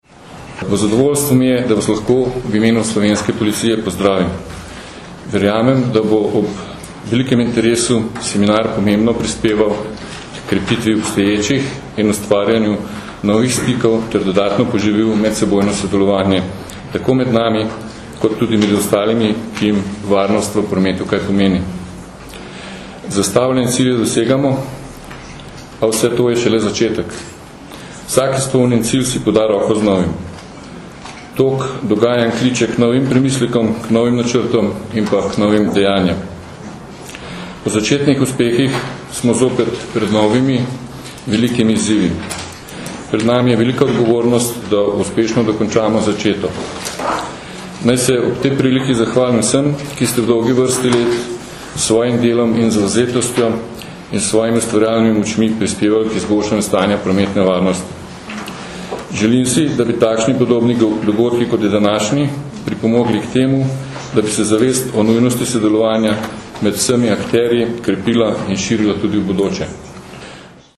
Na Bledu se je začel mednarodni strateški seminar o prometni varnosti
Zvočni posnetek nagovora